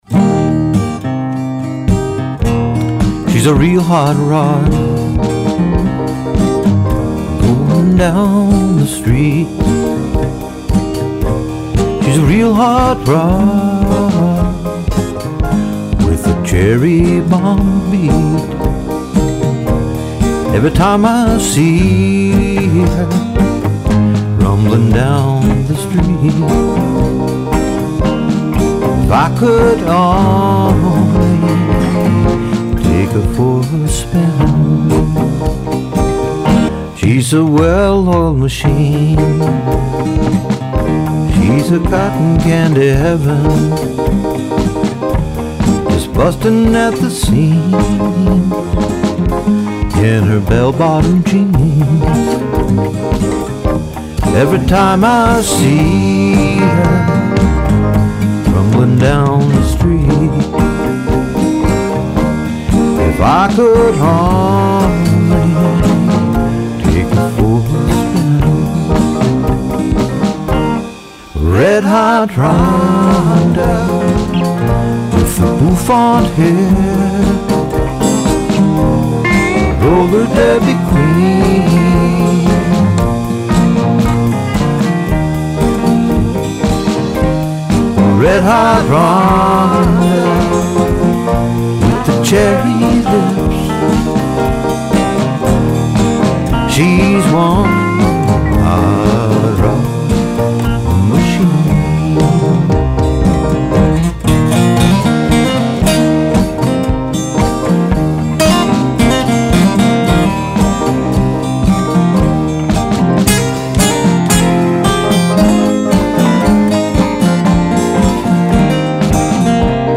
So Here’s the “demo” fo Red Hot Rhonda: